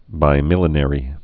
(bī-mĭlə-nĕrē, bīmə-lĕnə-rē)